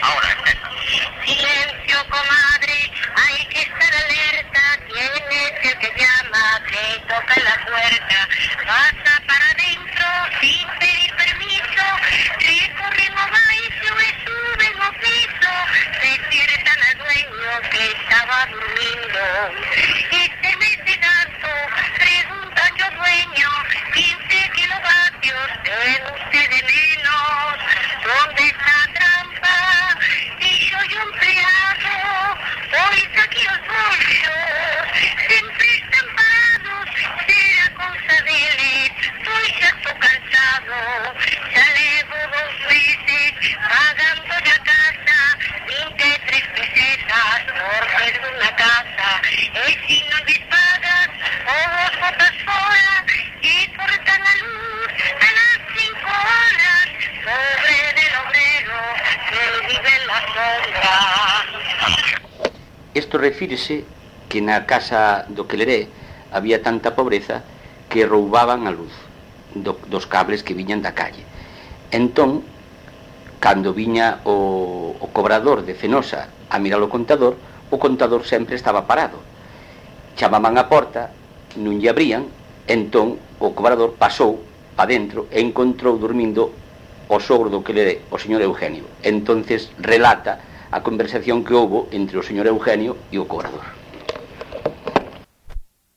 Palabras chave: copla de cego
LITERATURA E DITOS POPULARES > Cantos narrativos
Lugar de compilación: A Coruña
Soporte orixinal: Casete
Instrumentación: Voz
Instrumentos: Voz feminina